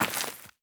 added stepping sounds
Glass_Grit_Mono_04.wav